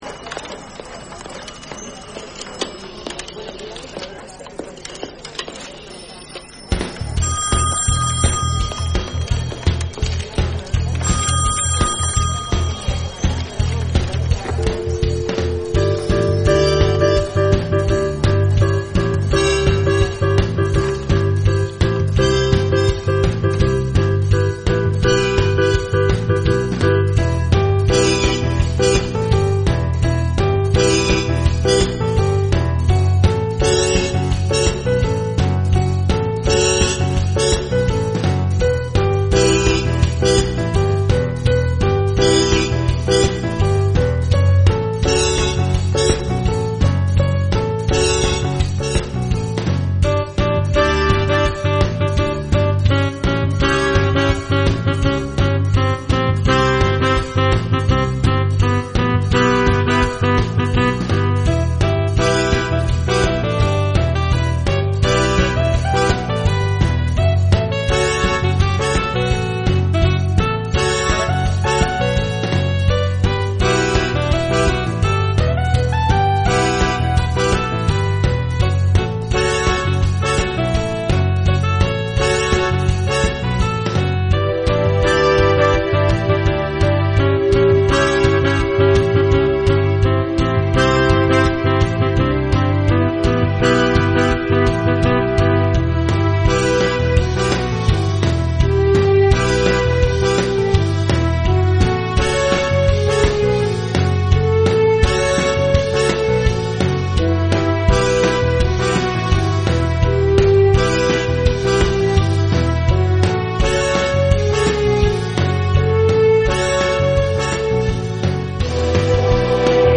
progressive rock band